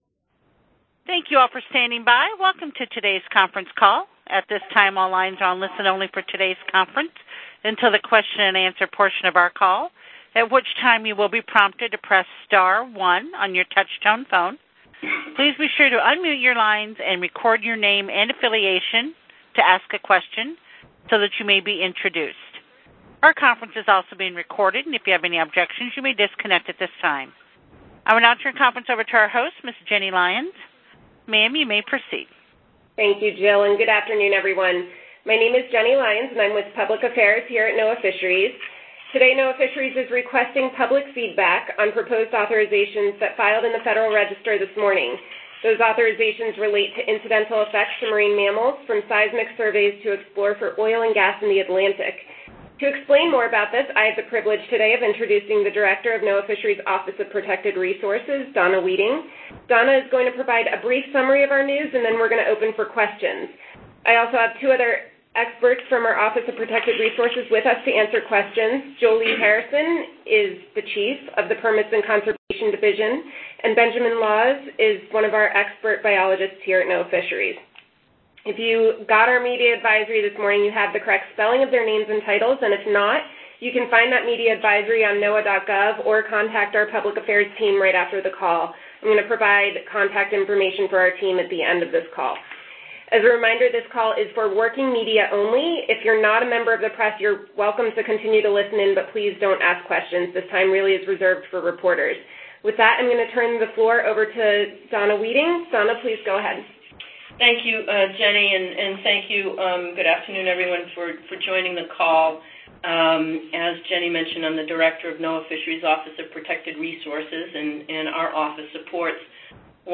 NOAA Fisheries will hold a media call on proposed authorizations regarding incidental effects to marine mammals from oil and gas seismic surveys, along with associated mitigation measures, during proposed oil and gas exploration in the Atlantic ocean.